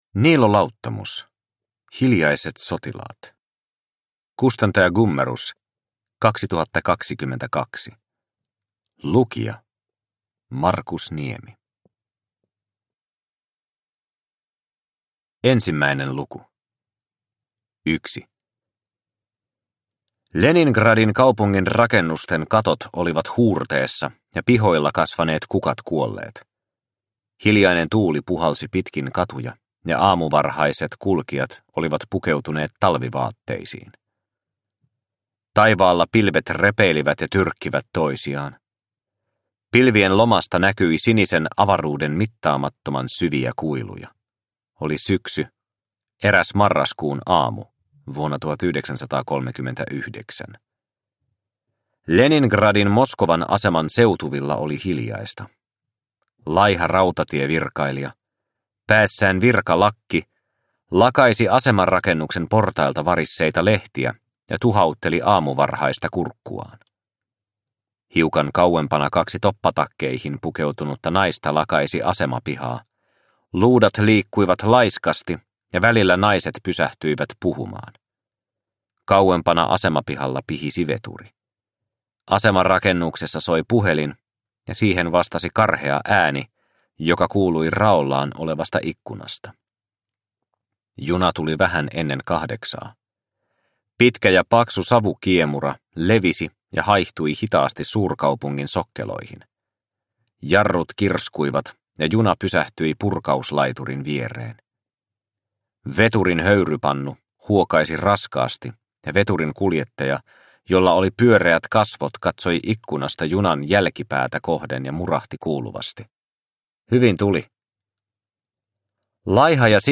Hiljaiset sotilaat – Ljudbok – Laddas ner